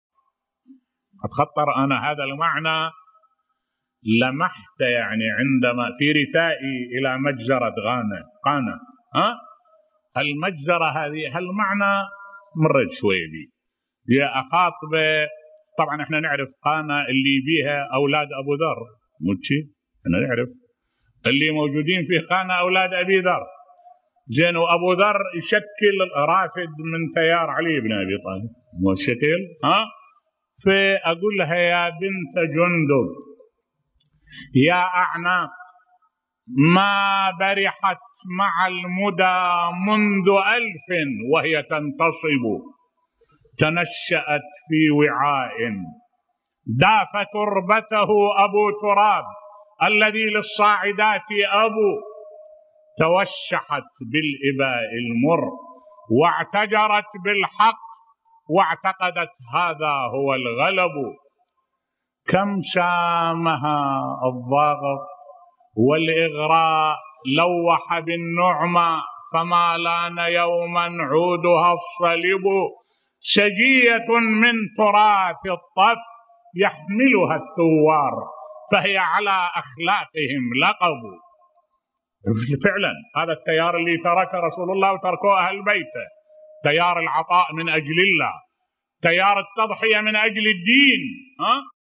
ملف صوتی رثاء الشيخ أحمد الوائلي لضحايا مجزرة قانا بصوت الشيخ الدكتور أحمد الوائلي